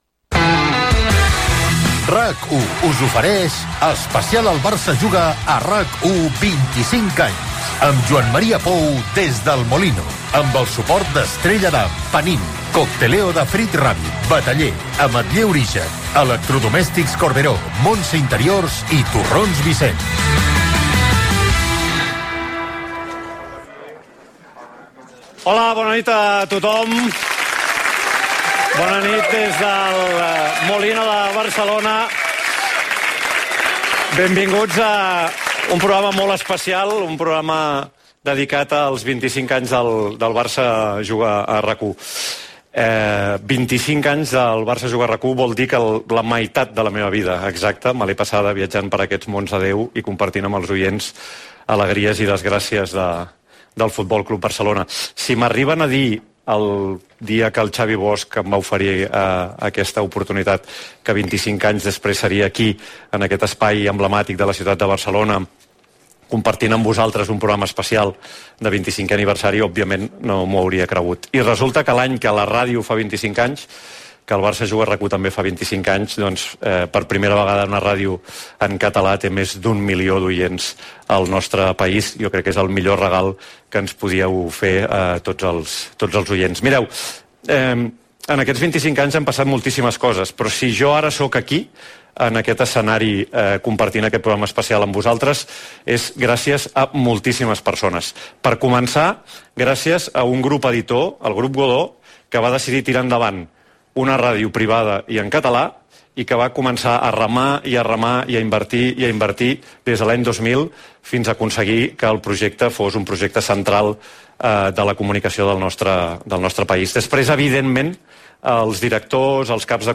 901695fb72d44a6d37556ff2190a580a930ecfda.mp3 Títol RAC 1 Emissora RAC 1 Barcelona Cadena RAC Titularitat Privada nacional Nom programa Especial el Barça Juga a RAC 1 25 anys Descripció Especial amb motiu dels 25 anys del programa, fet des de El Molino de Barcelona.
Esportiu